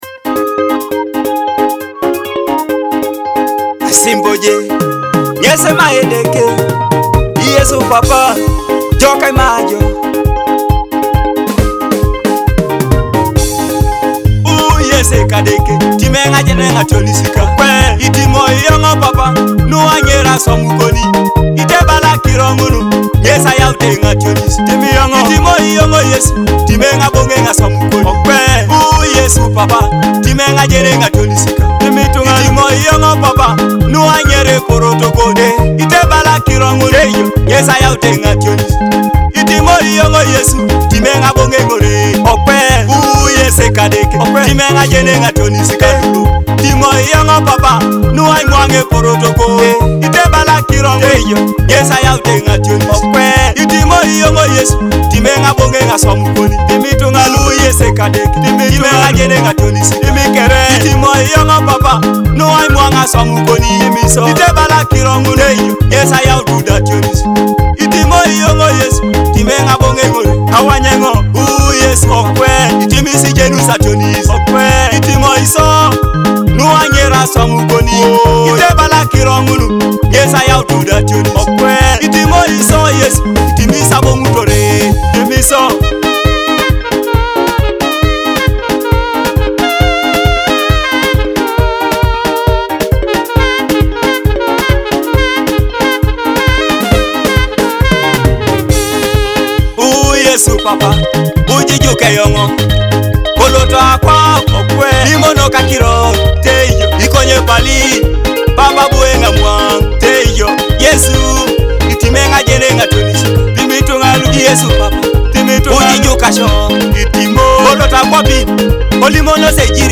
a powerful Teso gospel song of repentance and grace.
Teso gospel song
A soulful cry of repentance and grace.